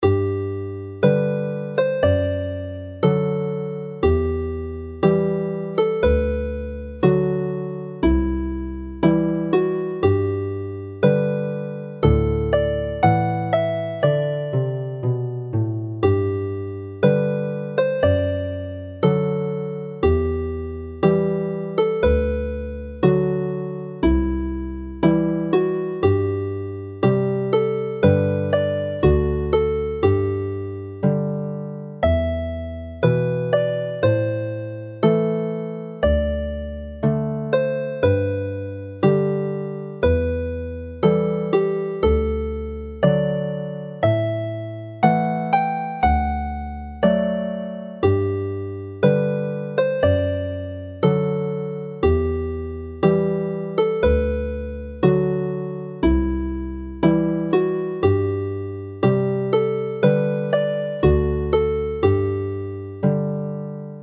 Hiraeth (Longing) is a well-known Welsh song which is in a pentatonic scale and when played on the harp in G only needs the uncoloured strings (D,E,G,A,B) and playing any combination of these notes as a simple accompaniment works!
Play the tune slowly